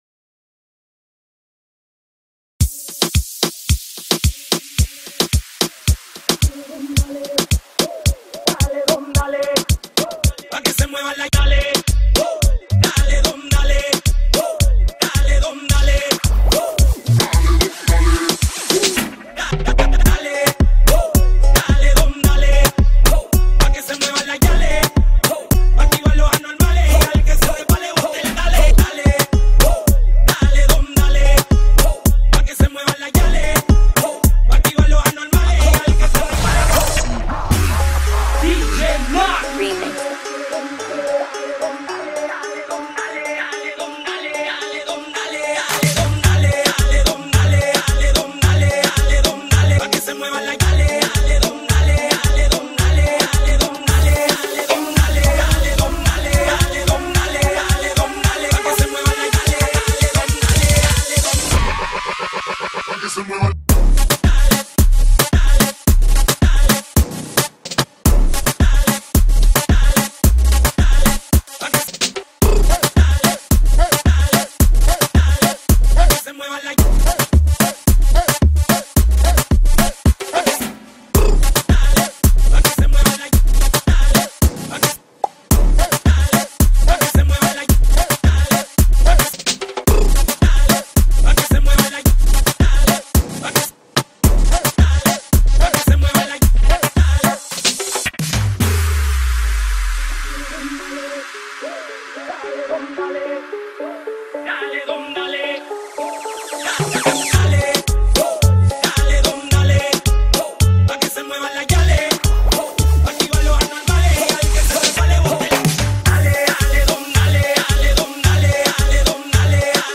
دانلود ریمیکس آهنگ اسپانیایی
بیس دار شاد